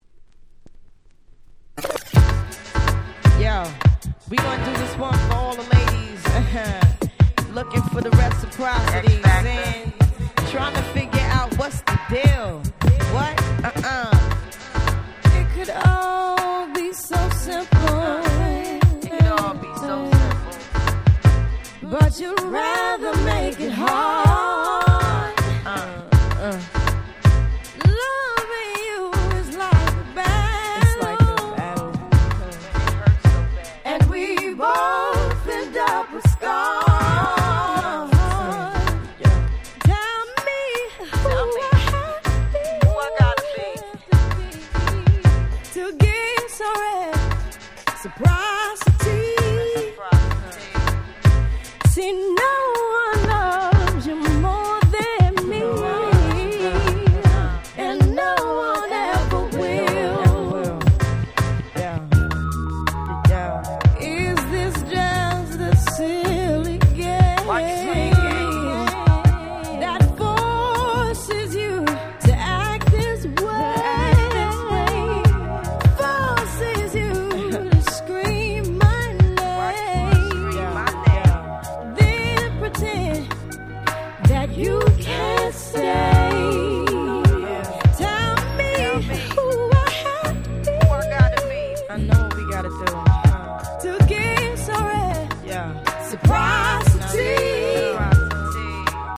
99' Super Hit R&B !!
オリジナルよりもNeo Soulに寄せた好Remixです！